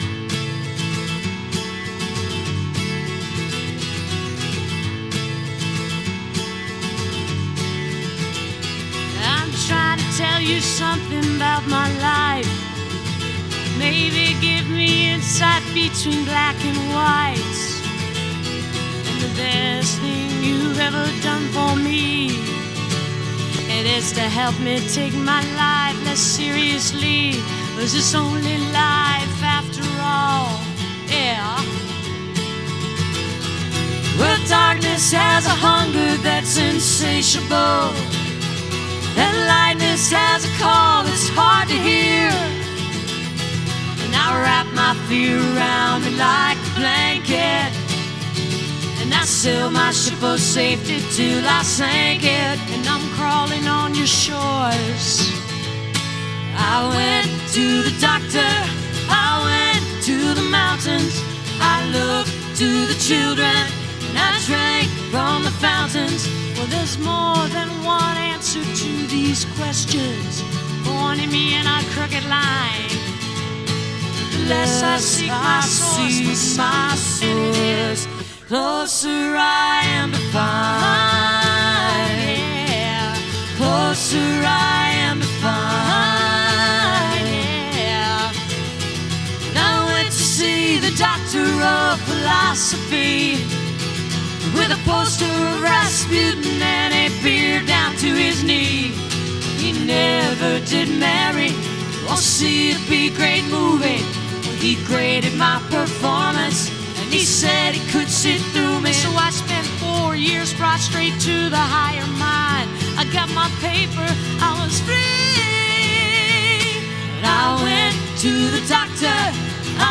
***performed backstage for channel 4 tv